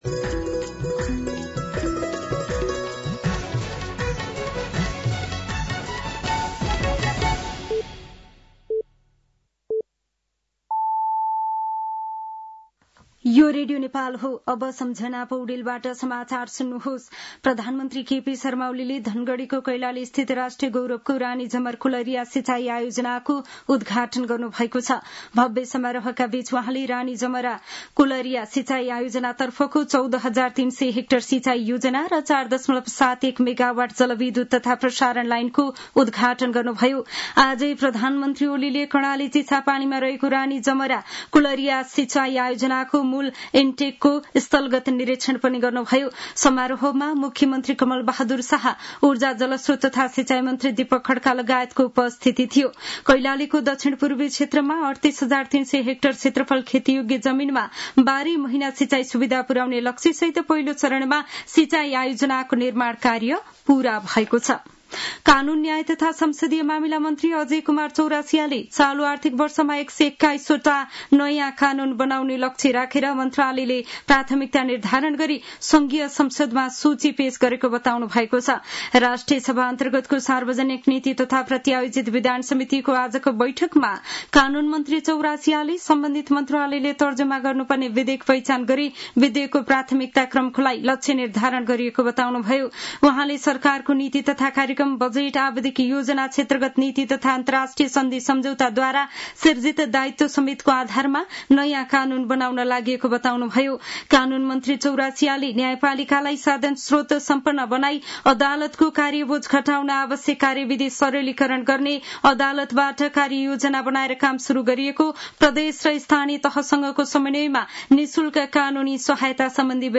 साँझ ५ बजेको नेपाली समाचार : १३ साउन , २०८२